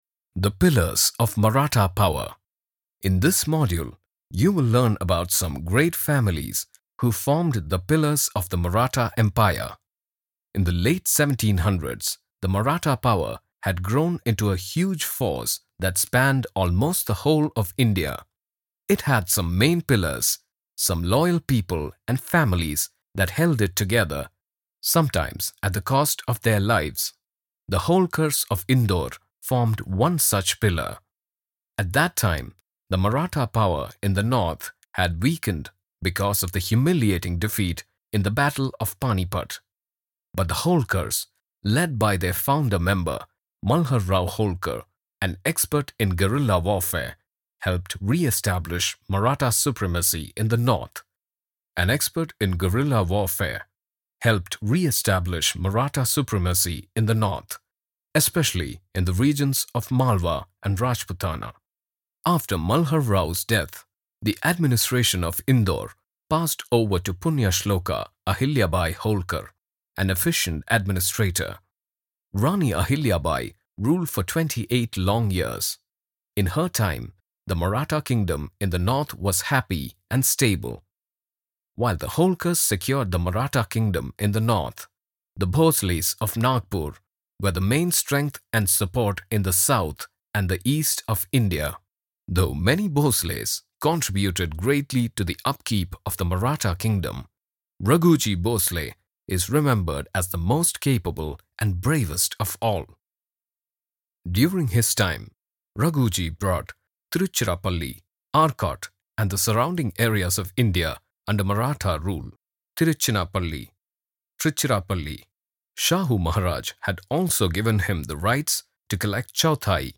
indisches engl.
Sprechprobe: eLearning (Muttersprache):
My accent is stylized neutral in English and being a trained musician, I have a wide vocal range which I put to good use with my voice recordings.